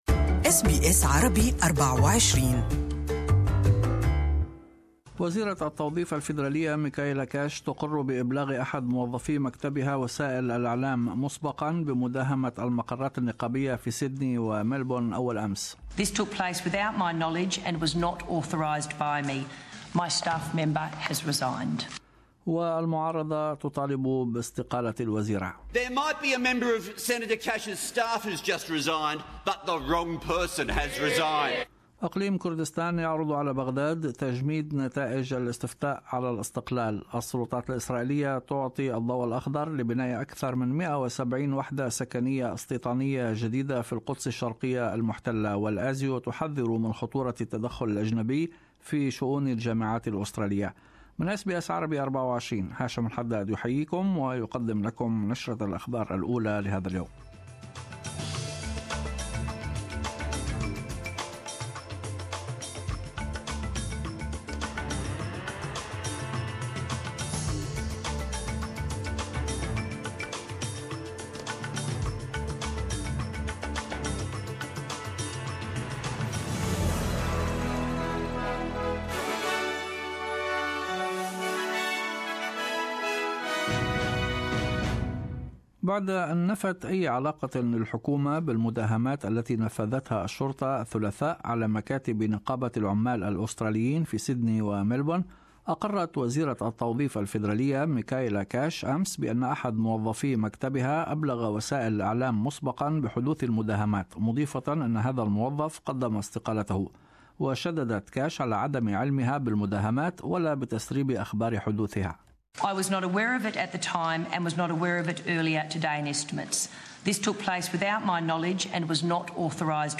In this bulletin ...